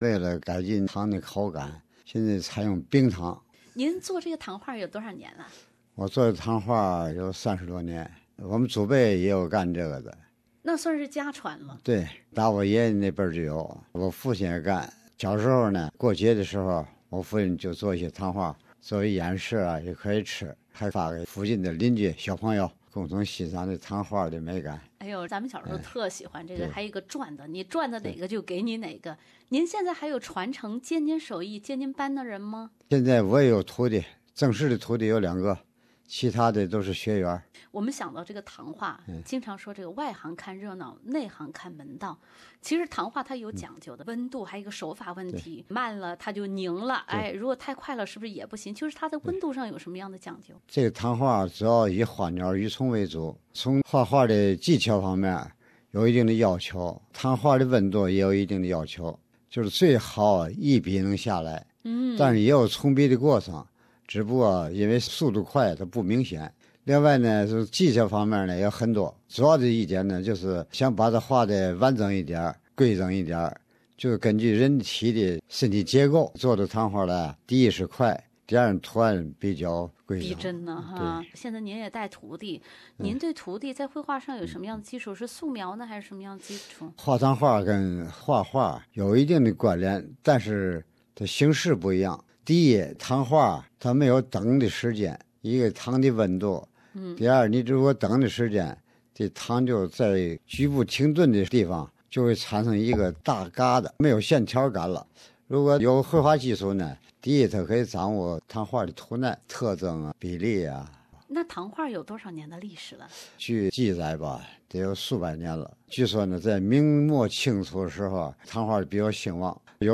而采访一开始，他首先给我们介绍了自家三代人与糖画的渊源。